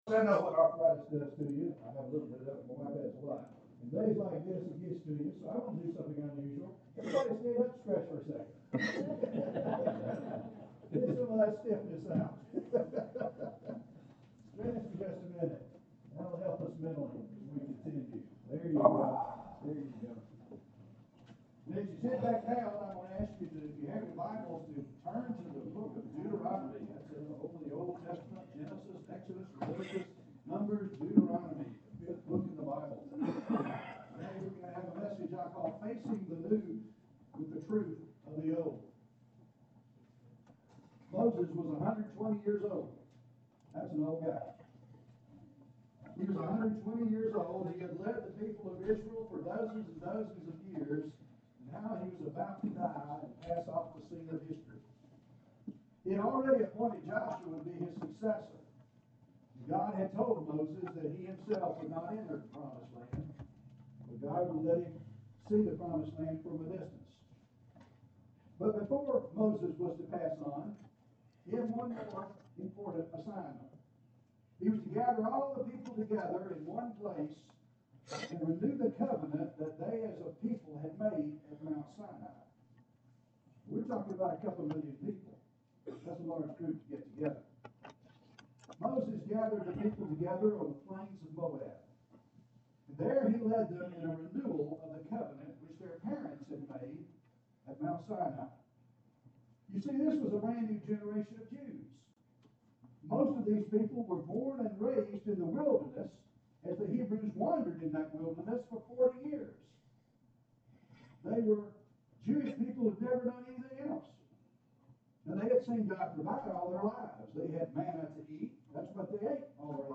Deuteronomy 29:10-15 & Deuteronomy 30:11-16 Sermon